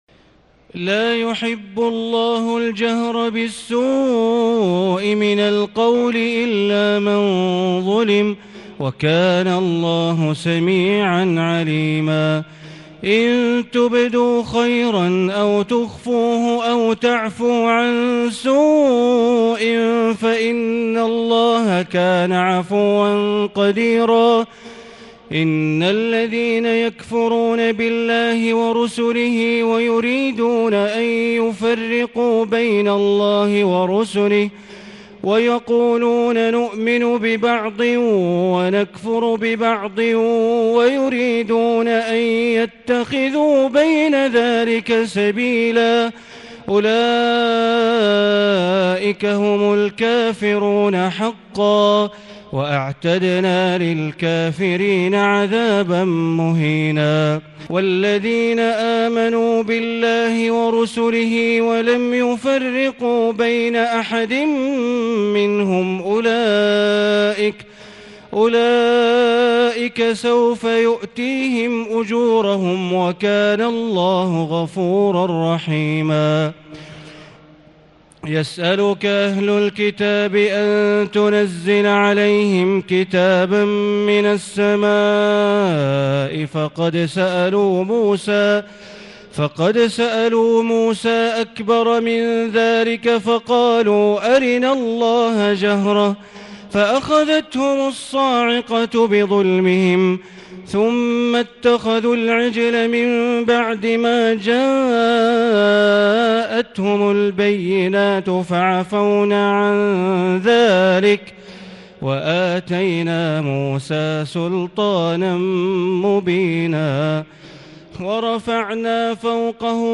تهجد ليلة 26 رمضان 1440هـ من سورتي النساء (148-176) و المائدة (1-40) Tahajjud 26 st night Ramadan 1440H from Surah An-Nisaa and AlMa'idah > تراويح الحرم المكي عام 1440 🕋 > التراويح - تلاوات الحرمين